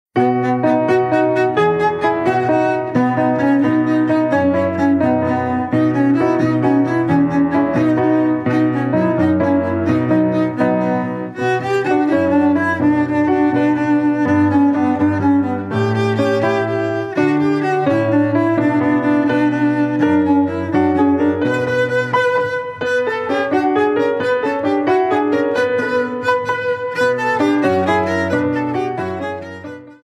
GRABADO EN planet estudio, Blizz producciónes
SOPRANO
VIOLONCELLO SOLO Y ENSAMBLES
PIANO